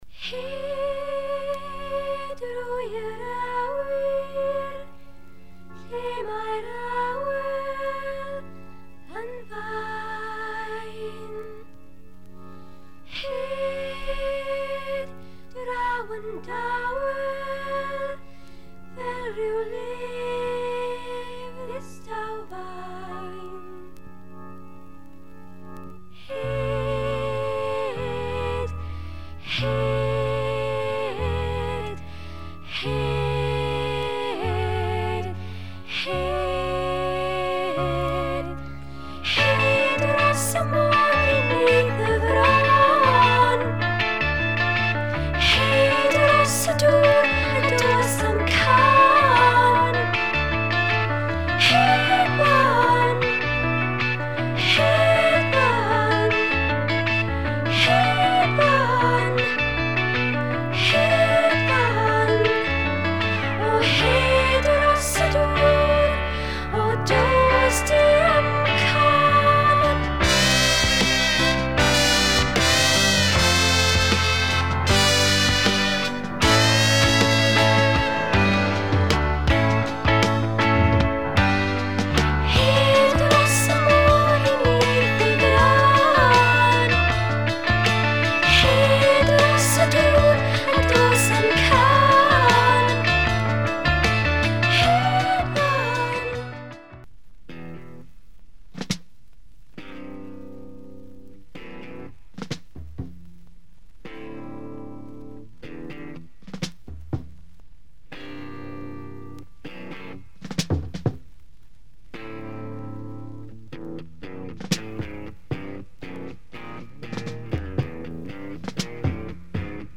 Welsh prog folk opera
Including some magnificent soul-folk gems